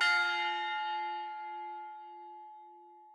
bell1_2.ogg